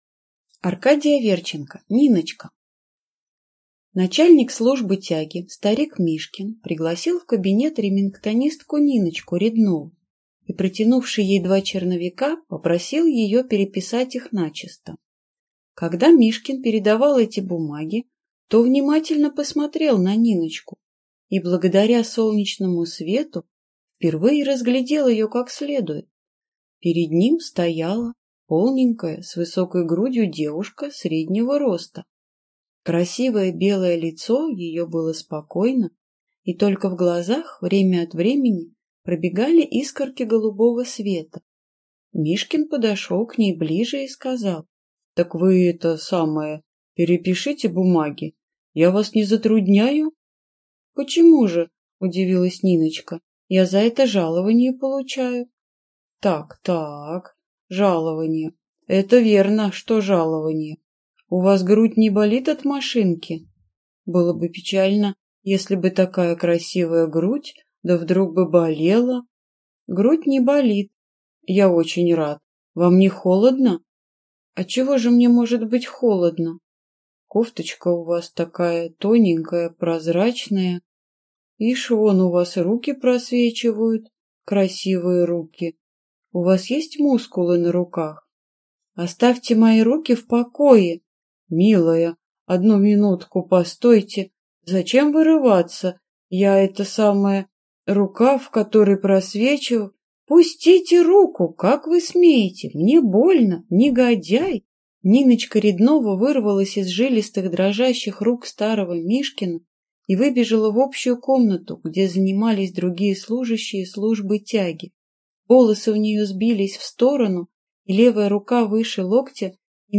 Аудиокнига Ниночка | Библиотека аудиокниг